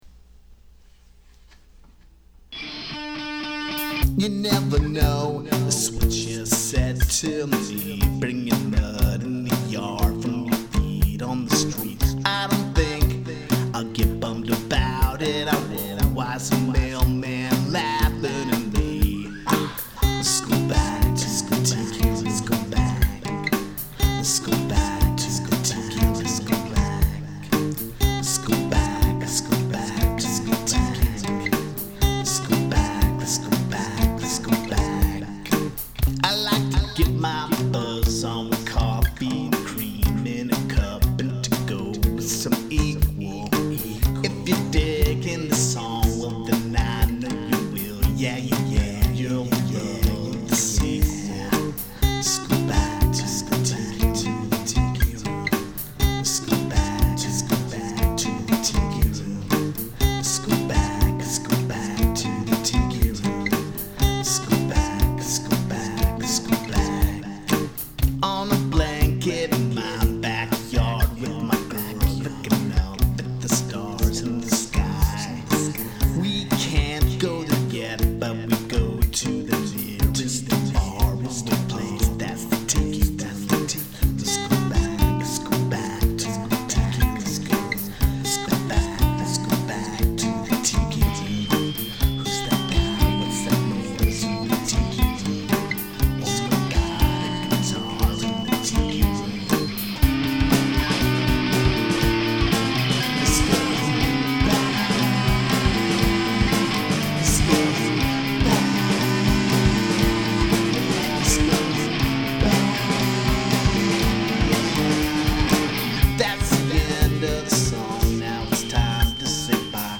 I played my Tiki Bongo song